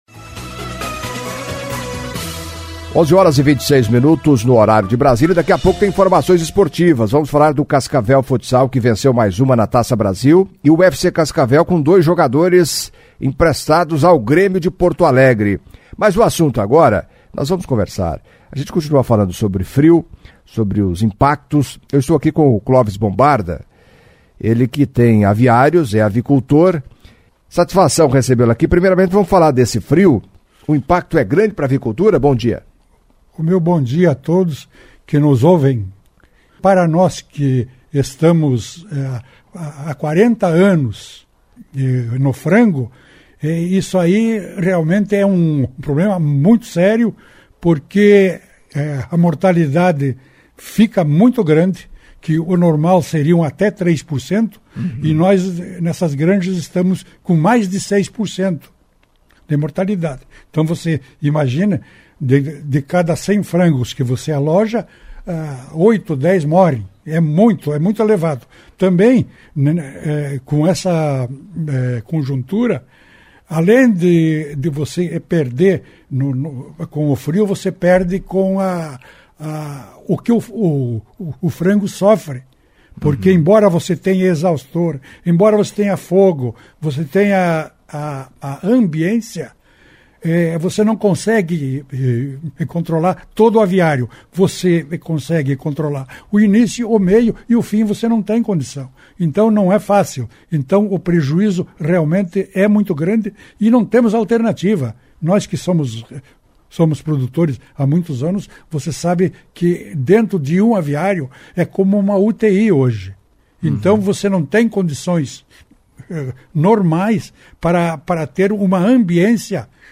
Em entrevista à CBN Cascavel nesta quinta-feira (29), o avicultor Clóvis Bombarda de Corbélia falou dos impactos do frio na produção de frangos. Bombarda que foi prefeito de Corbélia por dois mandatos, eleito em 1996 e 2000, diz que o PDT passa por uma restruturação e pensa em voltar a disputar cargo eletivo.